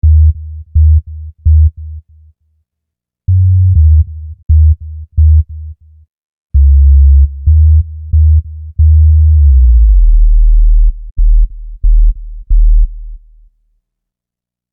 Bass 22.wav